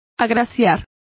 Complete with pronunciation of the translation of gracing.